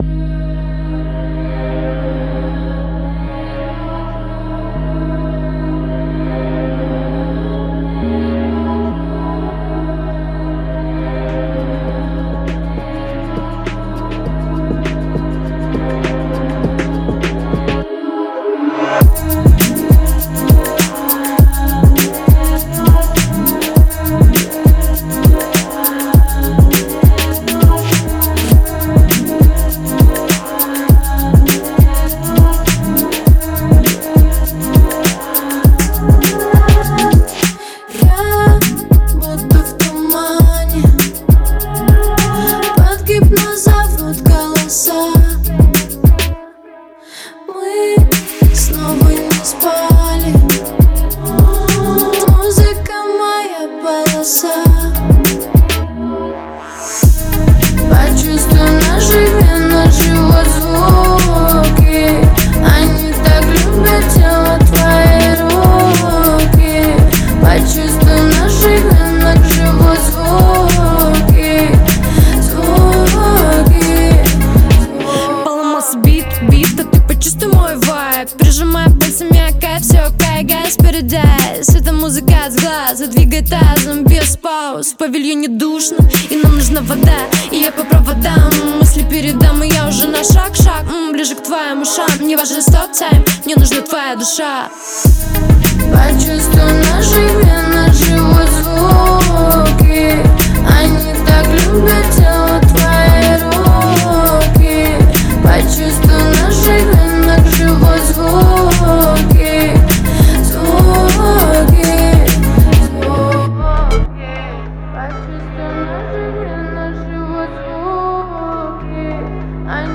Ворона_-_Звуки
Vorona___Zvuki.mp3